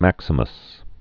(măksə-məs)